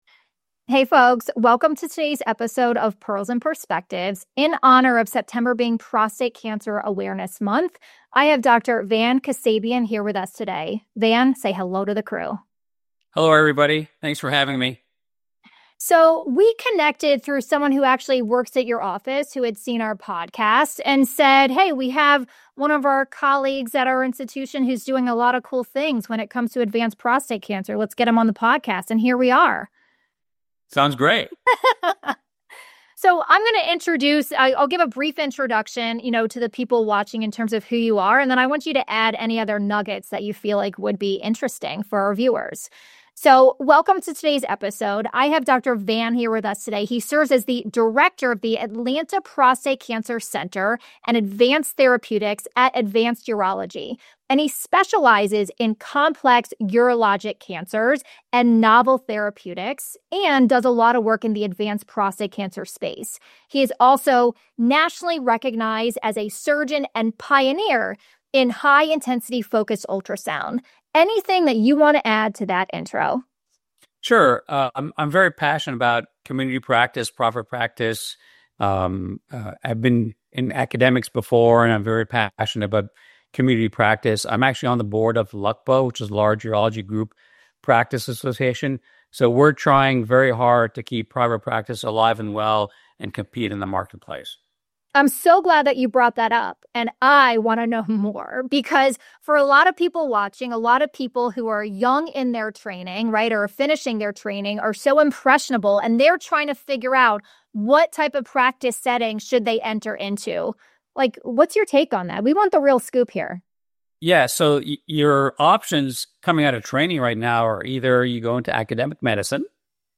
A major focus of the discussion is the integration of advanced prostate cancer therapeutics into urology offices.